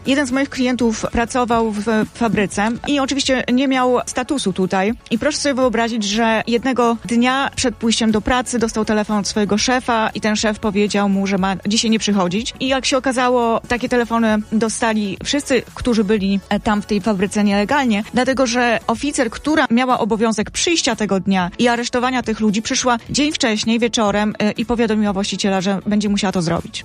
W studiu Radia Deon Chicago